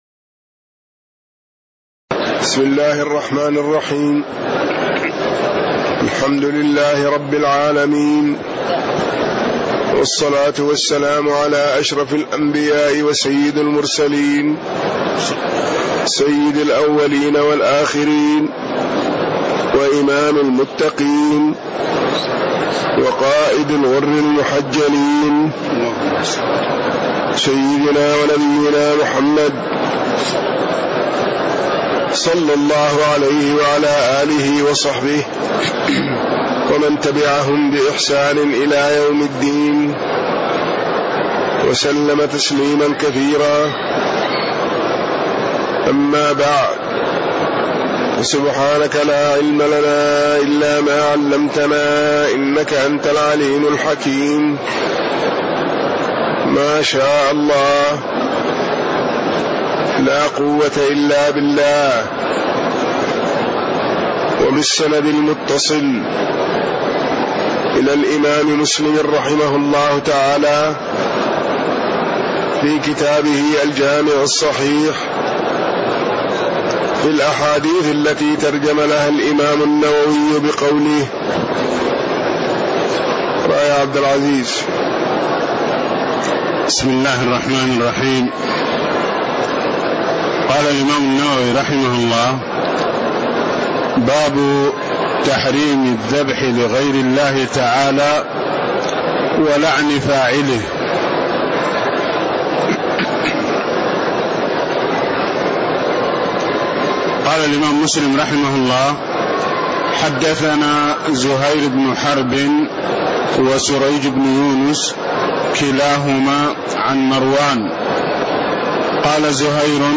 تاريخ النشر ١٠ جمادى الآخرة ١٤٣٦ هـ المكان: المسجد النبوي الشيخ